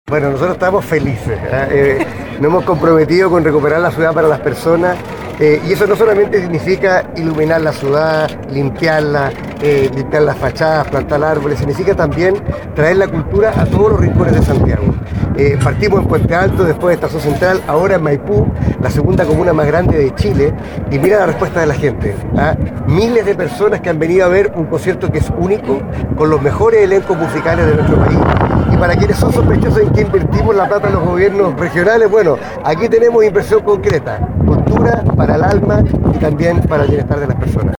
Por su parte el Gobernador de Santiago Claudio Orrego, nuevamente recalcó que los ciudadanos no solamente requiere de obras materiales , sino también requiere algo para el alma y el bienestar de las personas.
carmina-orrego-maipu.mp3